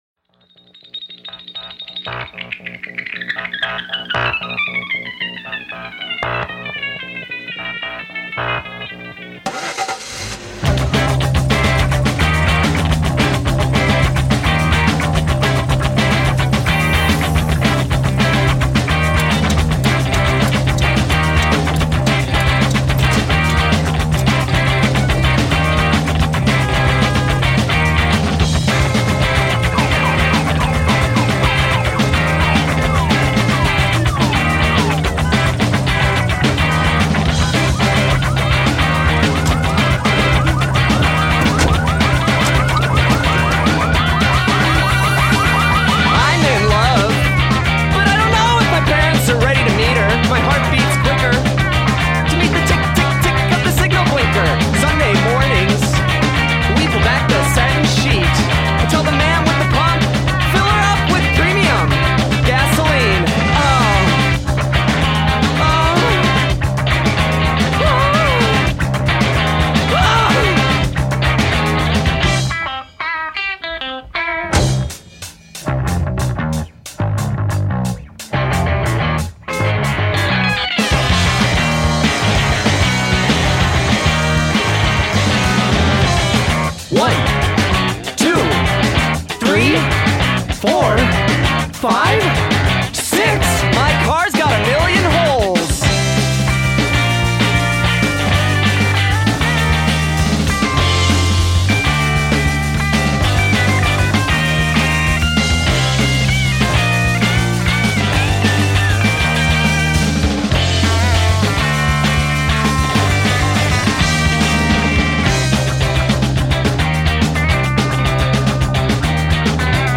post-punk/art rock band
” is a frenzied song about making love to your car.
bassist and singer
wiry track